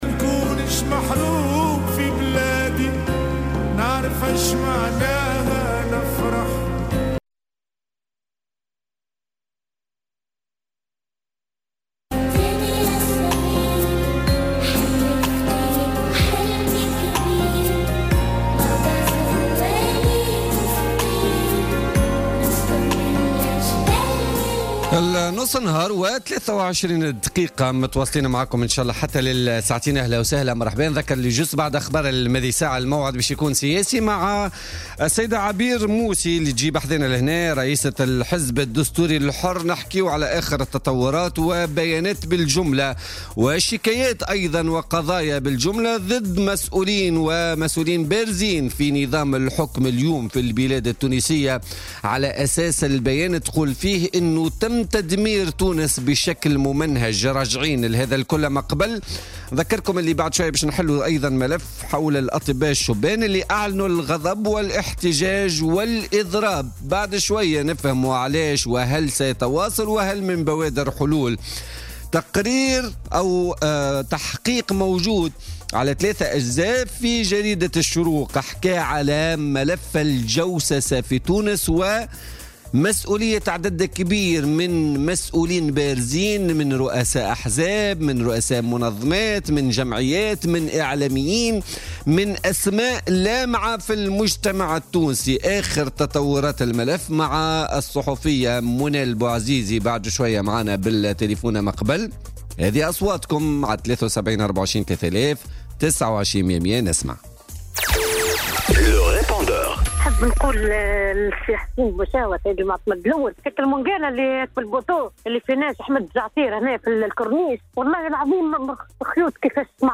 في اتصال مع "الجوهرة أف أم" في برنامج "بوليتيكا"